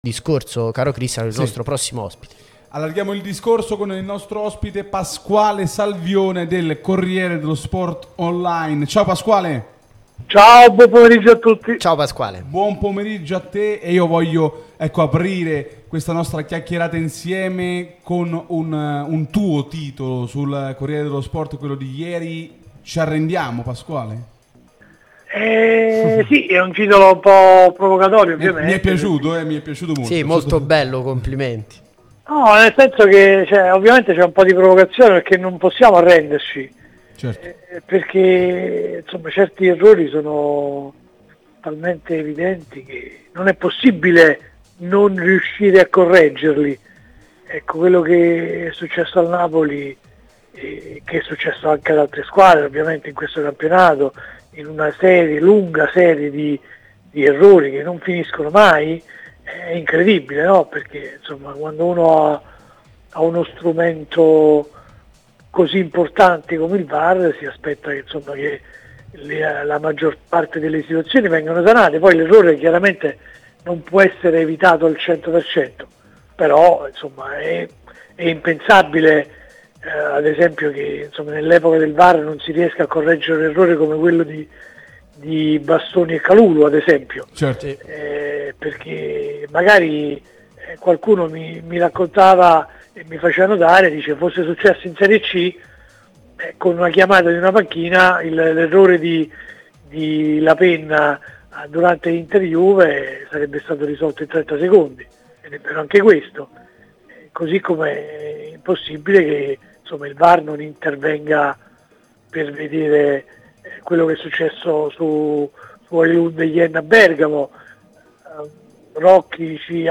è intervenuto nel corso di "Napoli Talk" sulla nostra Radio Tutto Napoli